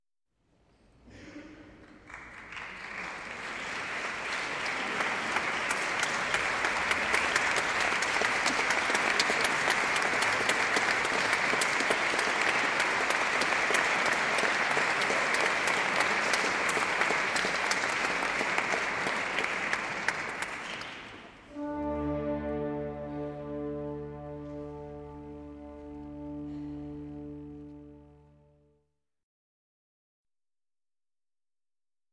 Звуки и возгласы толпы (сборка) Скачать звук music_note Люди , Общество , публика save_as 781.2 Кб schedule 0:50:00 4 0 Теги: mp3 , возглас , Голоса , звук , люди , общество , Публика , сборка , толпа